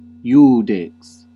IPA : /ˈdʒʌdʒ/